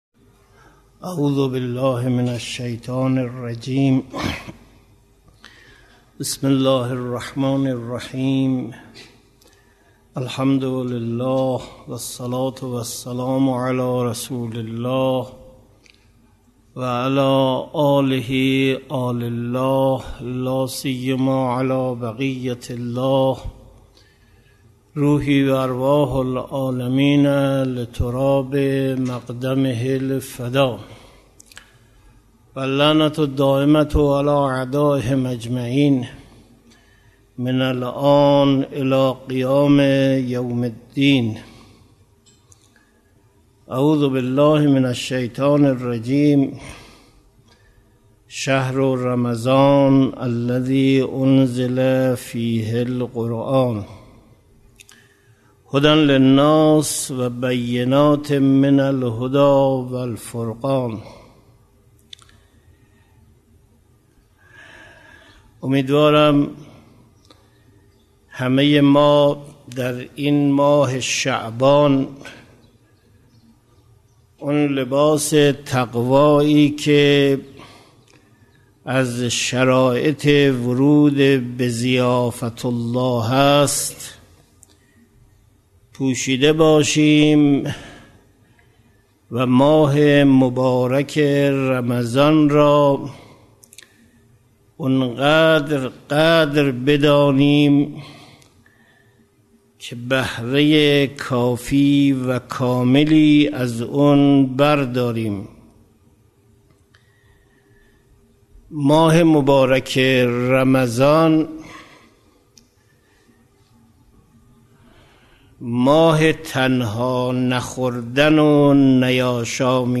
درس هشتم توضیح آیات